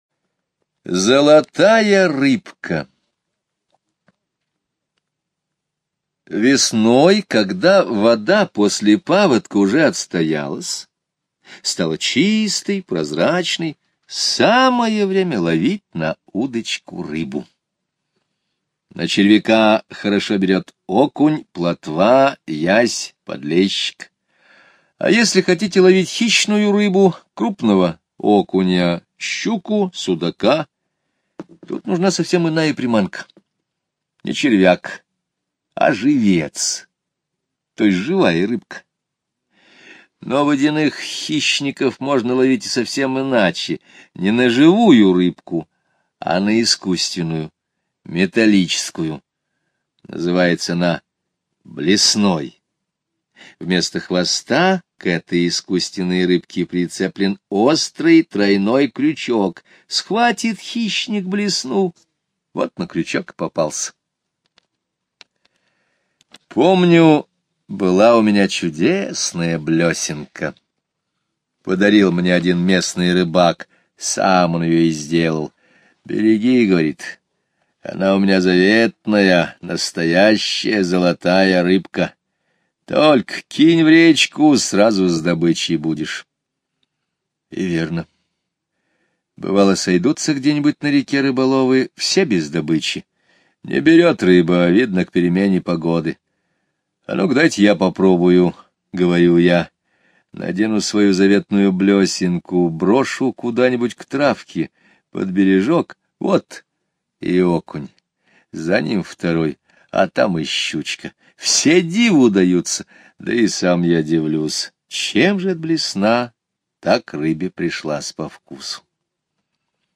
Золотая рыбка – Скребицкий Г.А. (аудиоверсия)
Аудиокнига в разделах